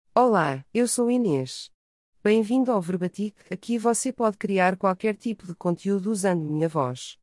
Inês — Female Portuguese AI voice
Inês is a female AI voice for Portuguese.
Voice sample
Female
Inês delivers clear pronunciation with authentic Portuguese intonation, making your content sound professionally produced.